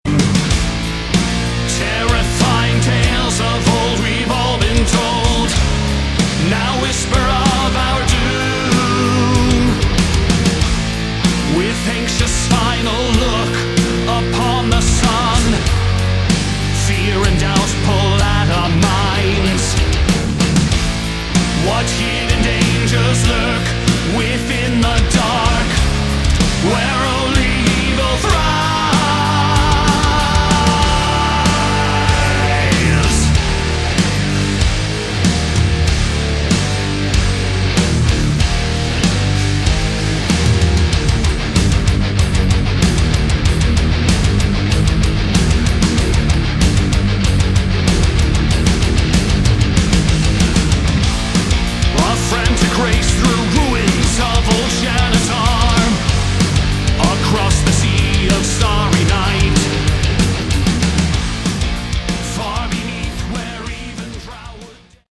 Category: Rock
bass guitar, backing vocals
drums, backing vocals
guitar, backing vocals
lead vocals, backing vocals